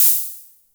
Modular Open Hat 01.wav